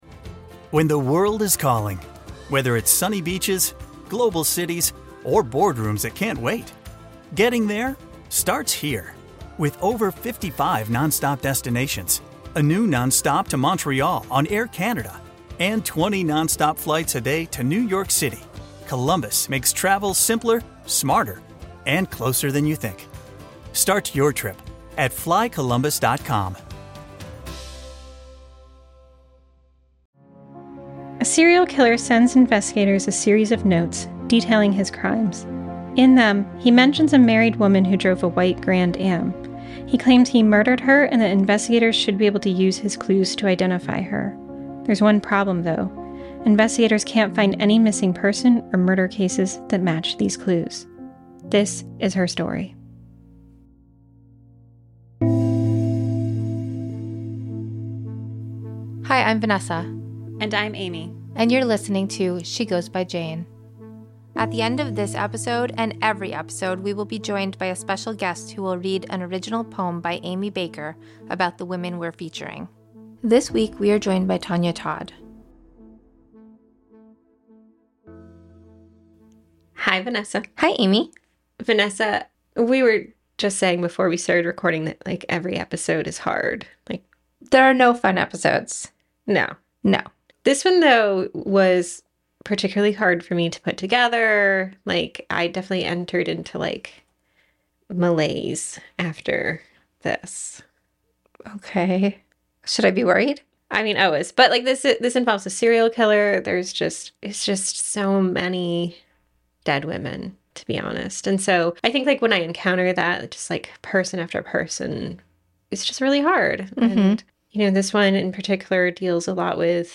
with guest reader